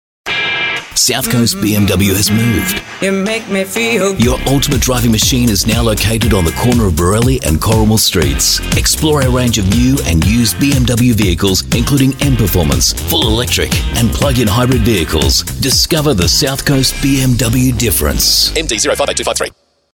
AU ENGLISH